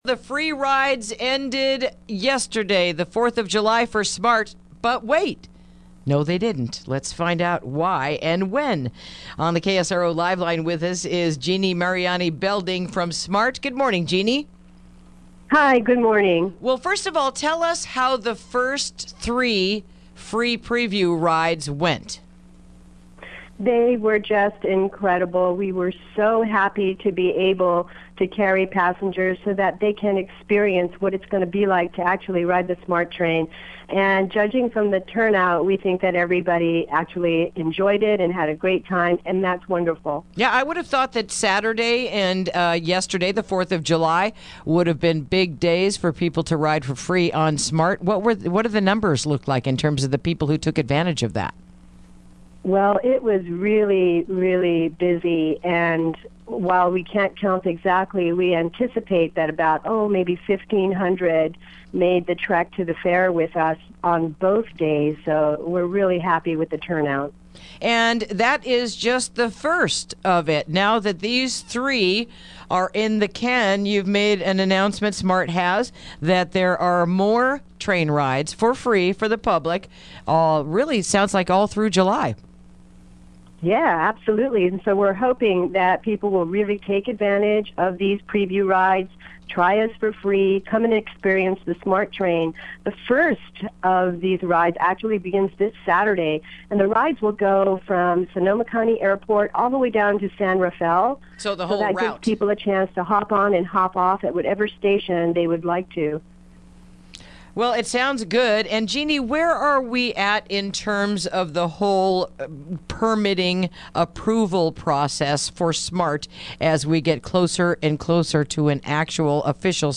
Interview: How SMART Preview Went and Upcoming Rides